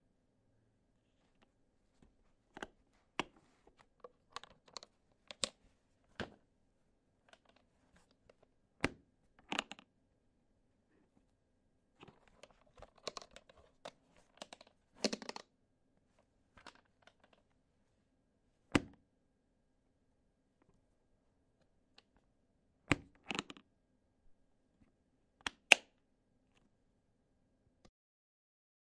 塑料公文包的打开和关闭
描述：打开和关闭带衬垫的塑料公文包，点击锁，锁定并解锁。